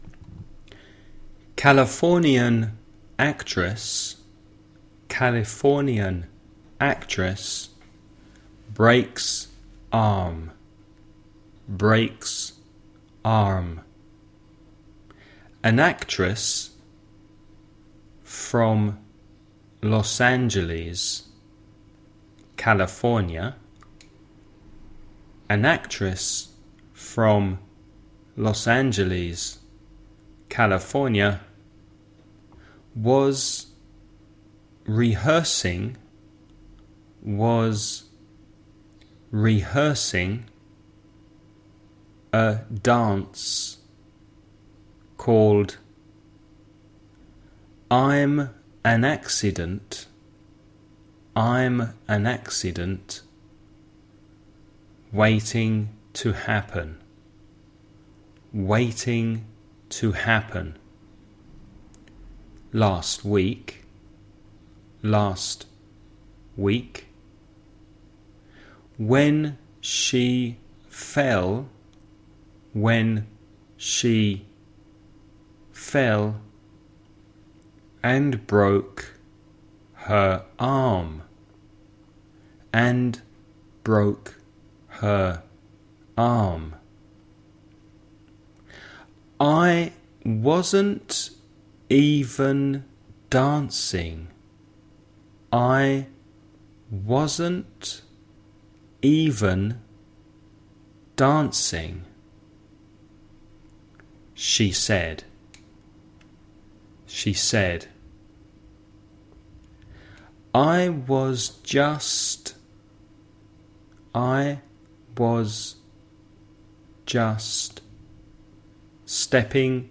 Dictados en Inglés - English dictations
dictation2.mp3